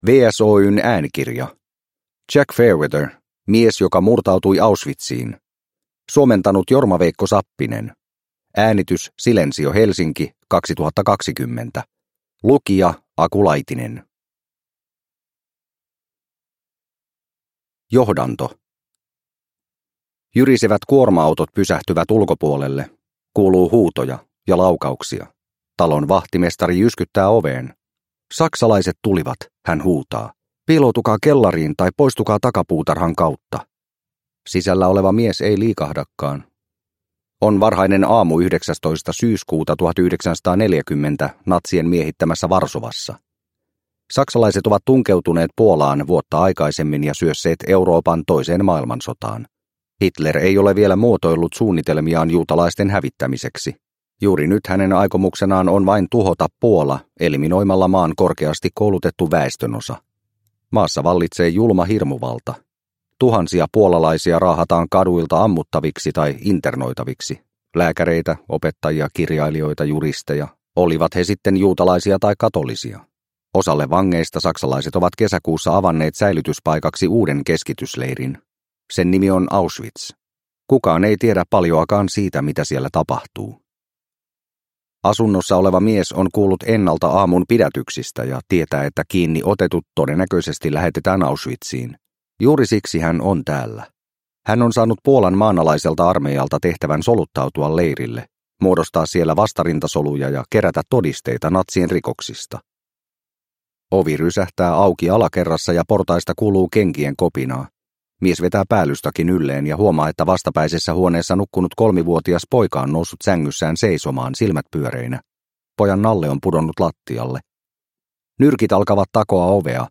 Mies joka murtautui Auschwitziin – Ljudbok – Laddas ner